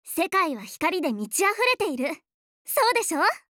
第三则配音片段：
善良、活泼、率真…丽的声音包含了太多可爱元素，它们全部被声优演绎得淋漓尽致。